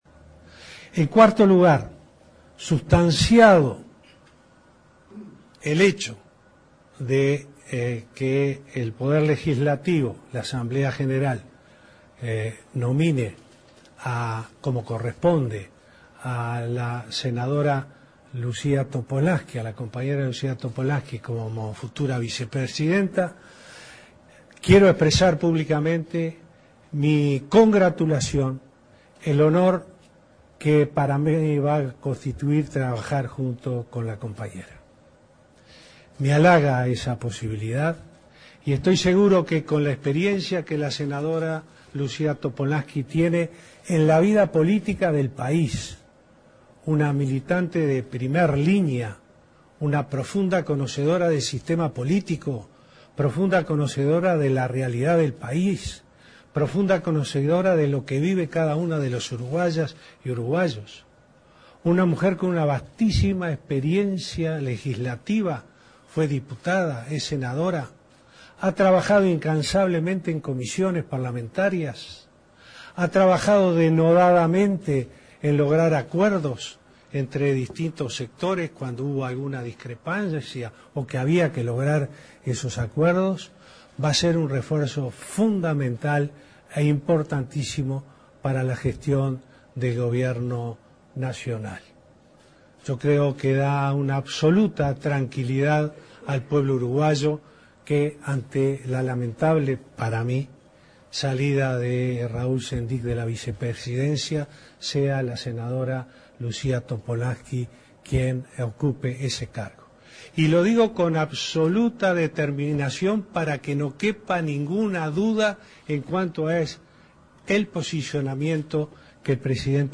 El presidente de la República, Tabaré Vázquez, dedicó la conferencia de prensa posterior al Consejo de Ministros para referirse a la renuncia presentada por el vicepresidente, Raúl Sendic, ante el Plenario del Frente Amplio.